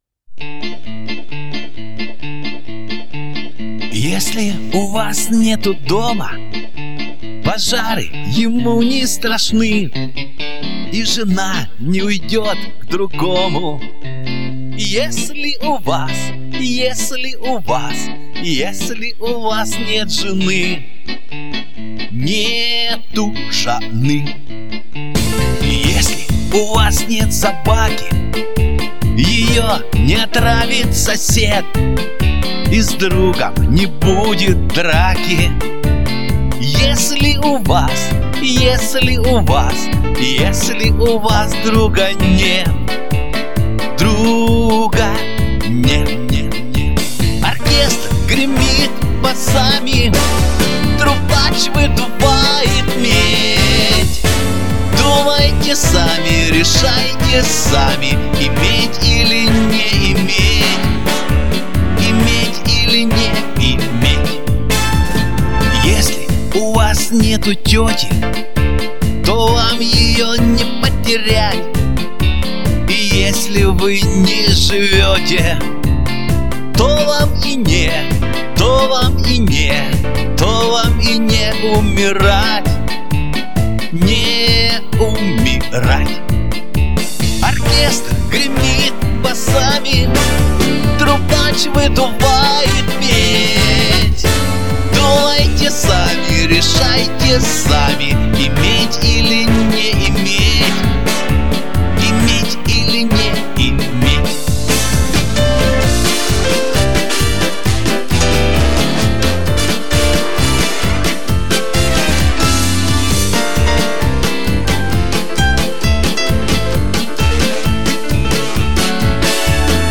Да и труба с сурдинкой нормально звучит.